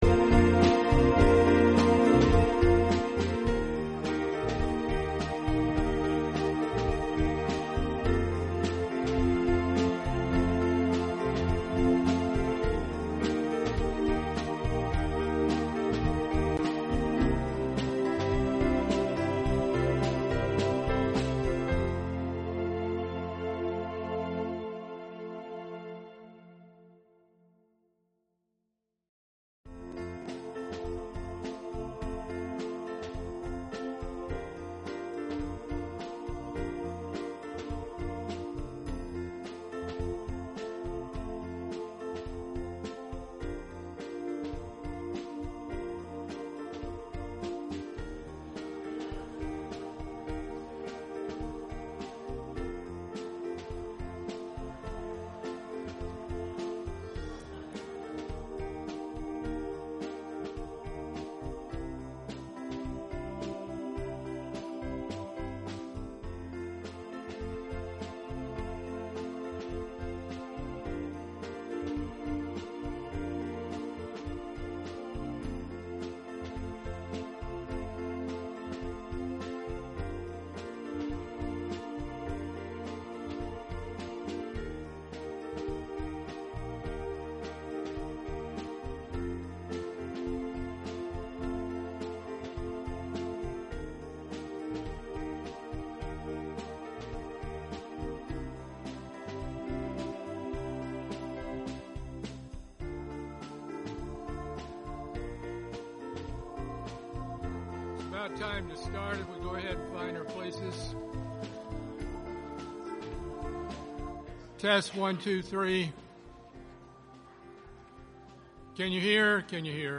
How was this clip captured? This sermon was given at the Panama City Beach, Florida 2018 Feast site.